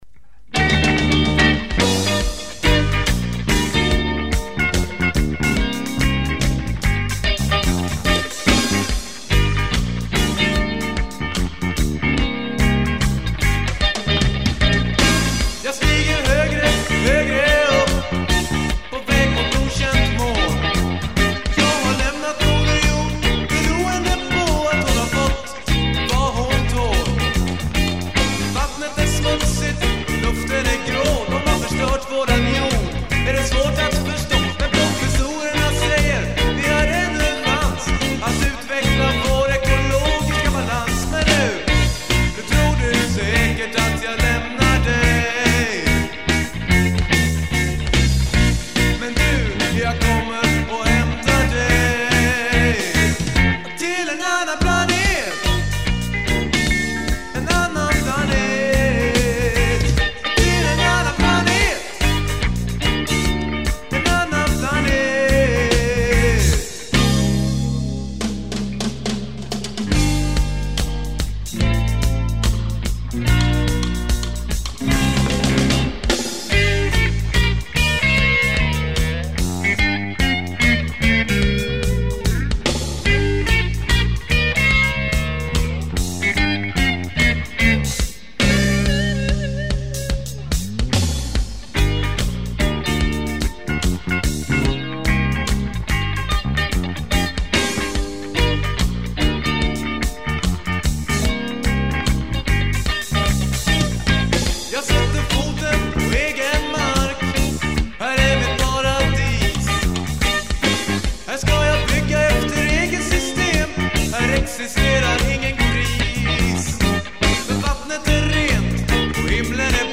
Guitar
Drums
Bass
Trumpet
Trombone
Saxophone